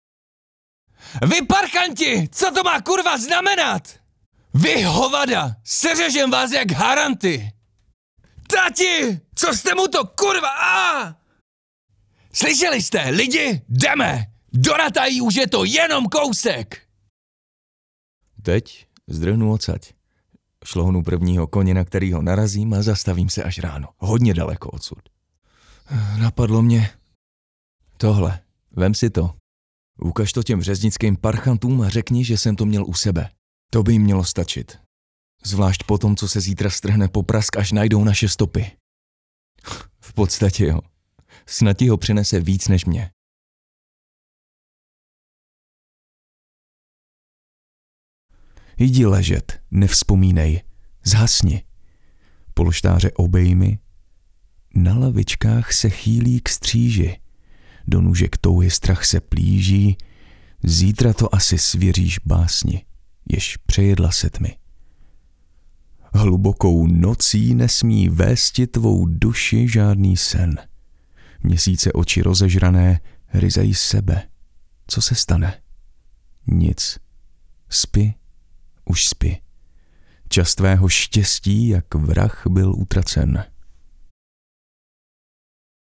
Mužský voiceover do Vašeho videa (Voiceover / 90 sekund)
Obdržíte kvalitní zvukovou stopu - voiceover (wav/mp3), vyčištěnou od nádechů a rušivých zvuků, nachystanou pro synchronizaci s vaším videem.
Dabing postav.mp3